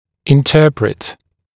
[ɪn’tɜːprɪt][ин’тё:прит]интерпретировать